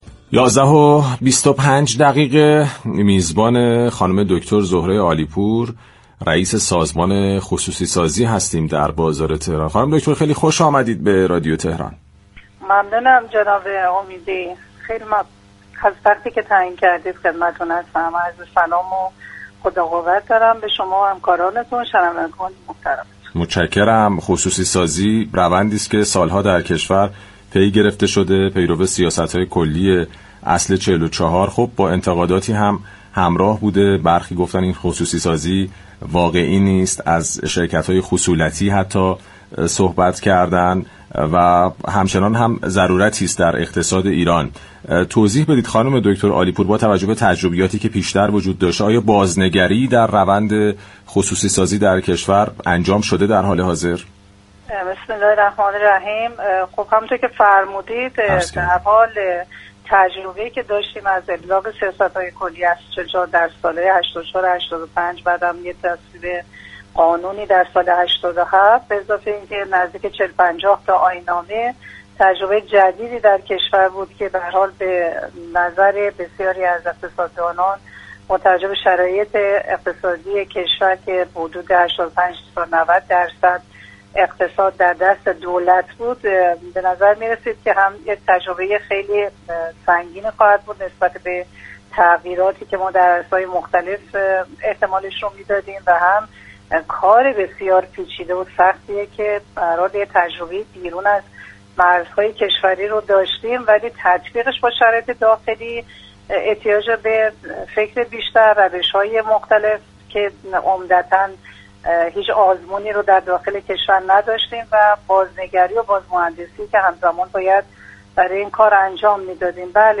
رئیس سازمان خصوصی‌سازی در گفتگو با رادیو تهران با تشریح روند اجرای اصل 44 قانون اساسی، به چالش‌ها و موانع پیش‌روی خصوصی‌سازی واقعی در ایران پرداخت و با اشاره به مقاومت‌های نهادی، مشكلات حقوقی و نبود شفافیت؛ ضرورت بازنگری در سیاست‌ها و اصلاح قوانین را برای تسریع انتقال شركت‌های دولتی به بخش خصوصی خواستار شد.